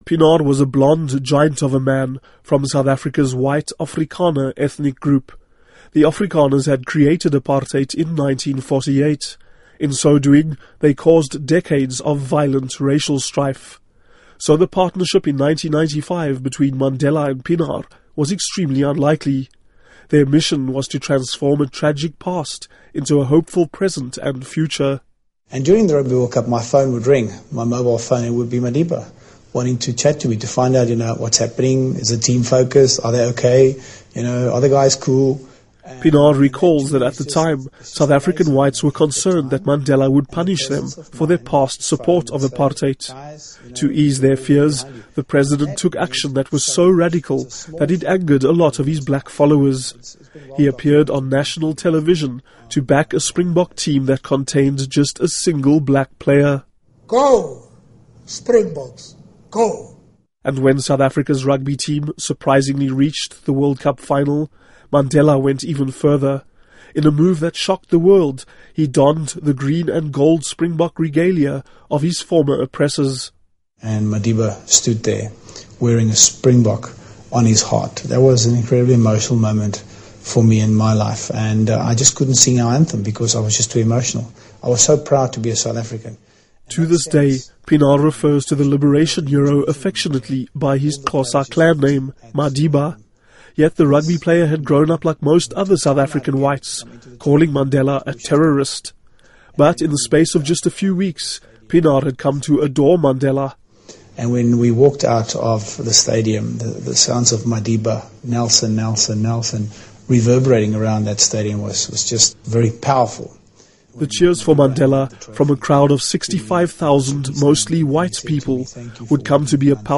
Listen to report with former Springbok captain, Francois Pienaar